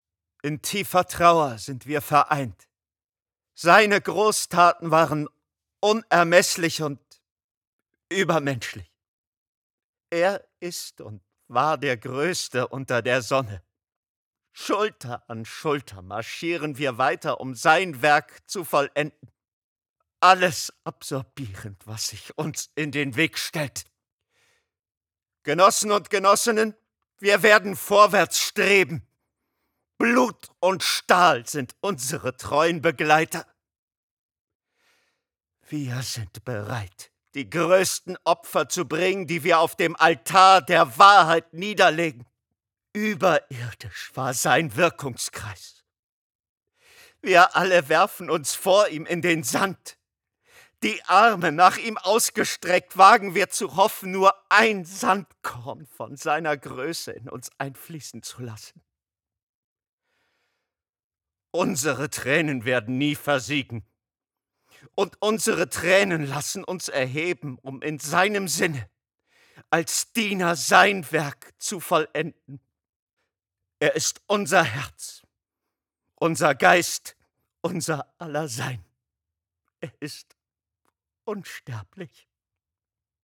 dunkel, sonor, souverän, markant, sehr variabel
Mittel minus (25-45)
Hörspiel - Fanatiker
Audio Drama (Hörspiel)